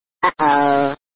fail3.mp3